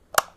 switch25.ogg